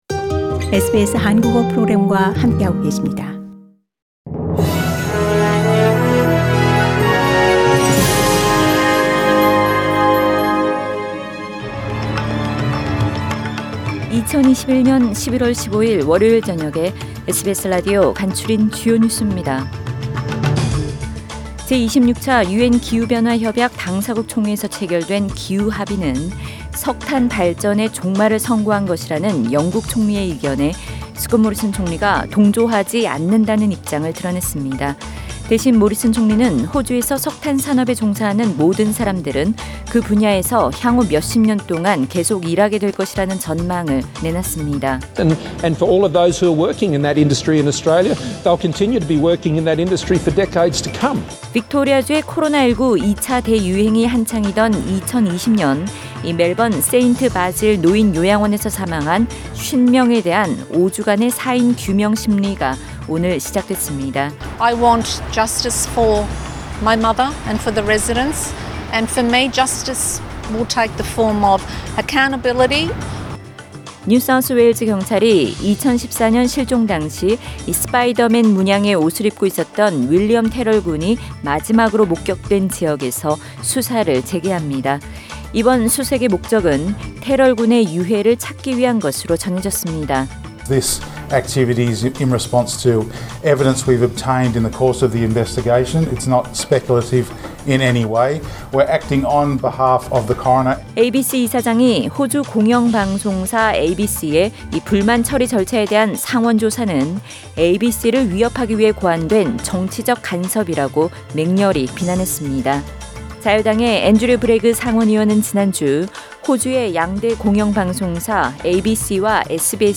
2021년 11월 15일 월요일 저녁의 SBS 뉴스 아우트라인입니다.